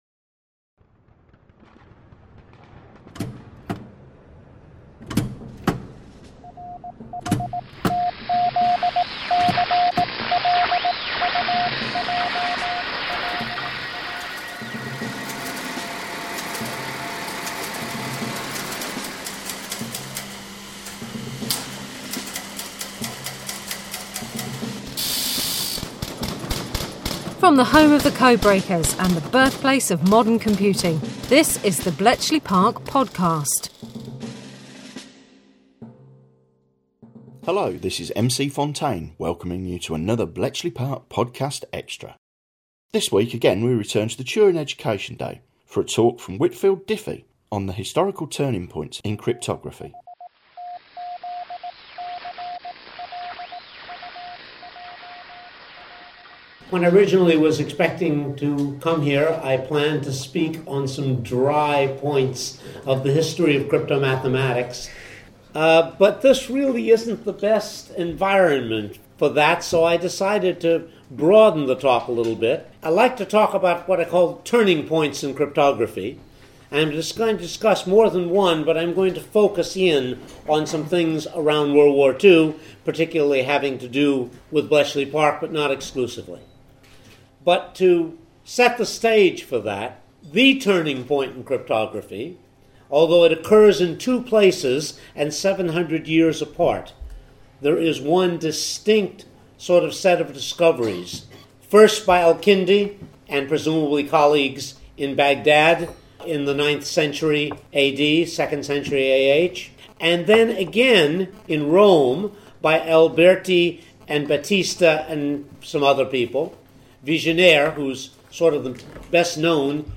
Extra - E09 - Whitfield Diffie Bletchley Park Bletchley Park History 4.8 • 177 Ratings 🗓 31 October 2012 ⏱ 26 minutes 🔗 Recording | iTunes | RSS 🧾 Download transcript Summary October 2012 This week we have another talk from the Turing Education Day. Whitfield Diffie gives a talk on historical turning points in cryptography.